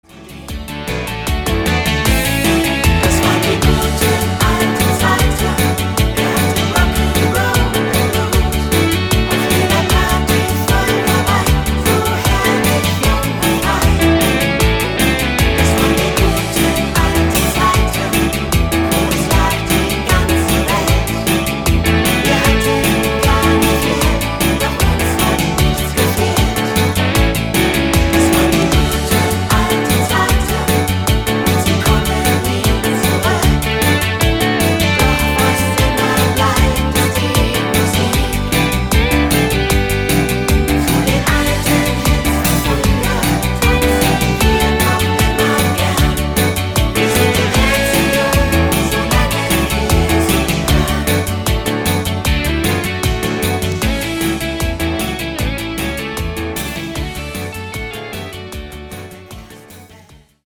Rhythmus  8 Beat Rock'n Roll
Art  Deutsch, Party Hits, Schlager 2020er